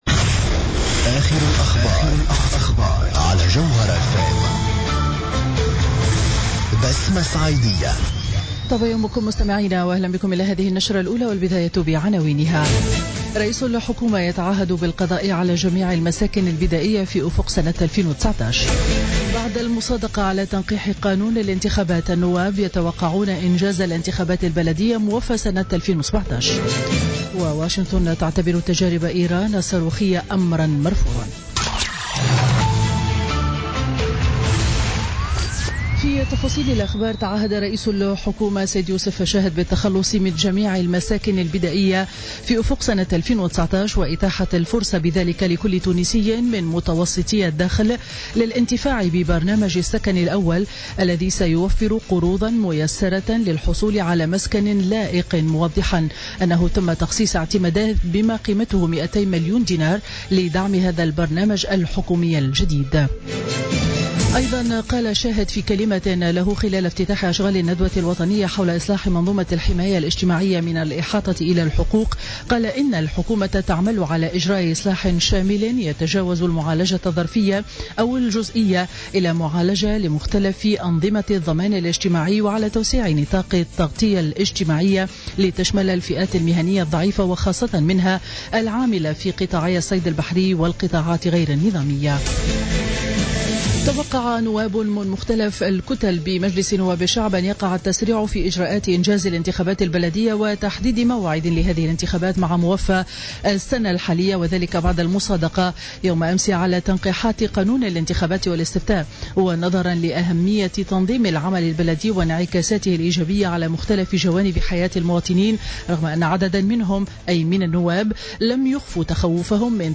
Journal Info 07h00 du mercredi 1er février 2017